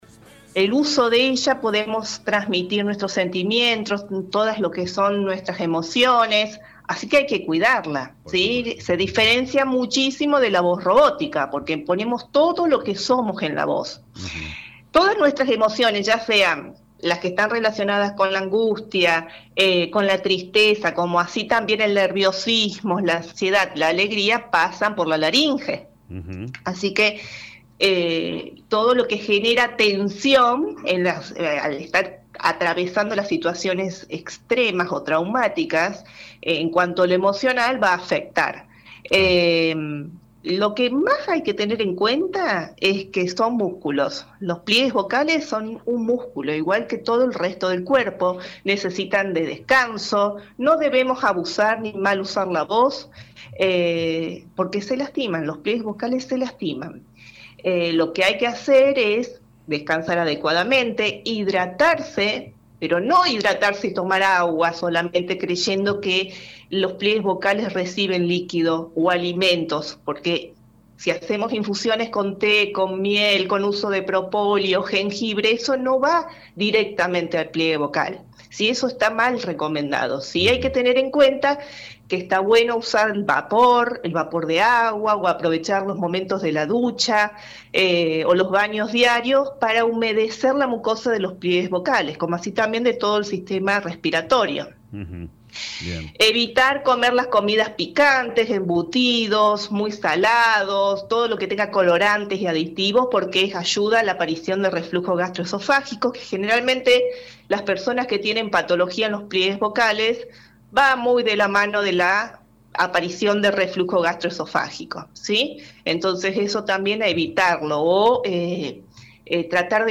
ENTREVISTA – LIC.